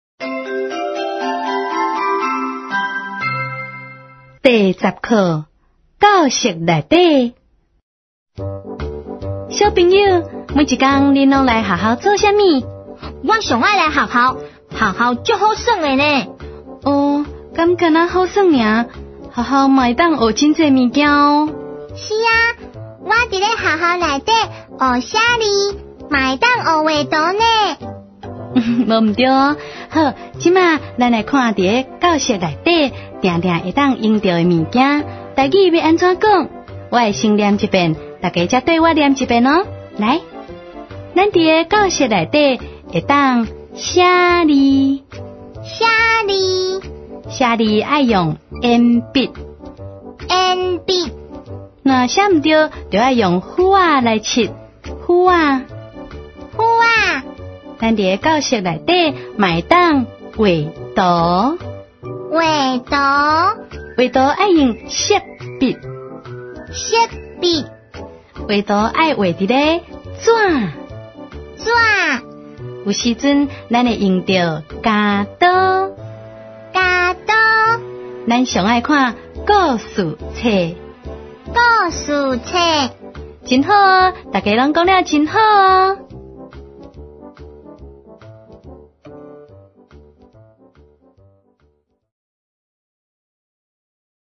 ● 兒歌唱遊、常用語詞 ●